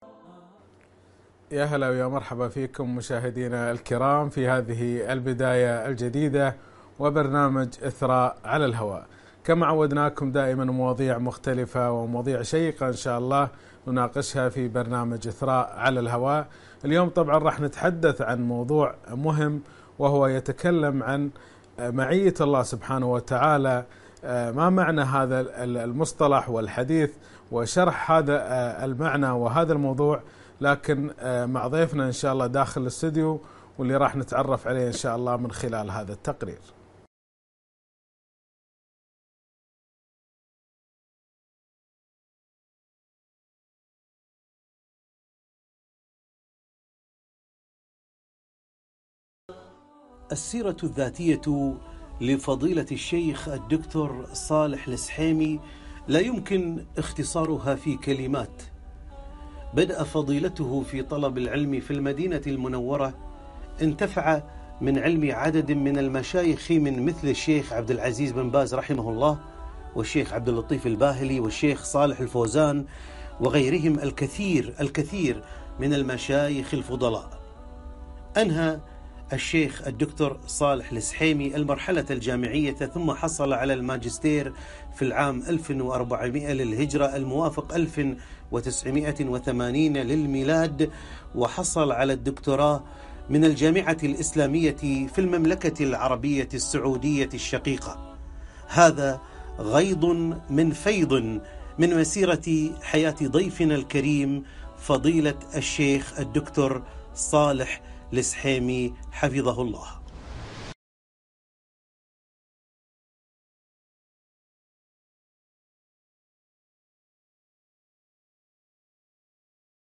لقاء بعنوان : معية الله تلفزيون الكويت برنامج إثراء على الهواء